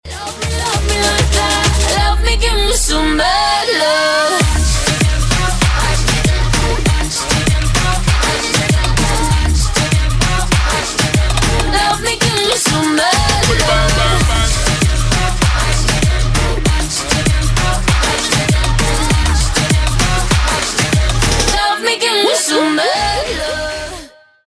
• Качество: 320, Stereo
поп
громкие
женский вокал
заводные
dance
Latin Pop